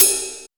percussion 23.wav